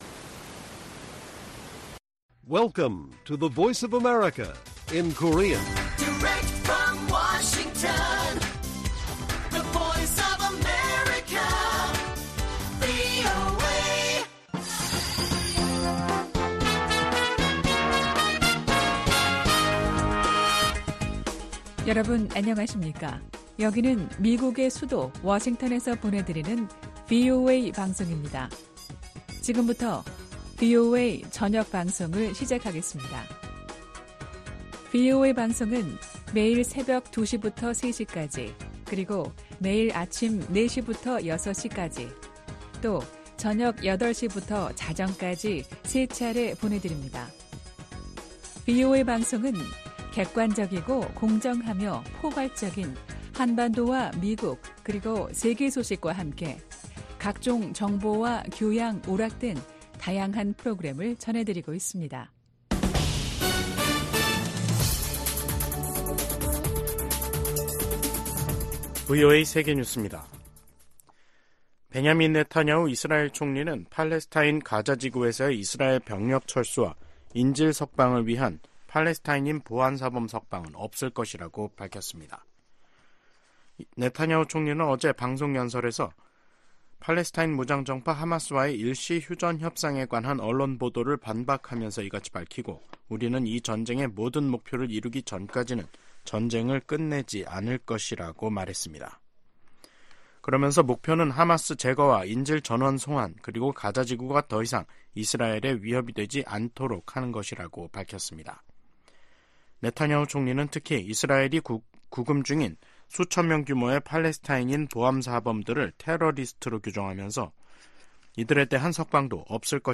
VOA 한국어 간판 뉴스 프로그램 '뉴스 투데이', 2024년 1월 31일 1부 방송입니다. 윤석열 한국 대통령은 북한이 총선을 겨냥한 도발을 벌일 것이라며 총력 대비해야 한다고 강조했습니다. 미 공화당 하원의원들이 자동차업체 포드 사와 계약을 맺은 중국 업체들이 북한 정부 등과 연계돼 있다고 밝혔습니다. 국무부는 북한이 10대 소년들이 한국 드라마를 봤다는 이유로 12년 노동형을 선고받았다는 보도에 대해 처벌의 가혹성을 깊이 우려한다”고 밝혔습니다.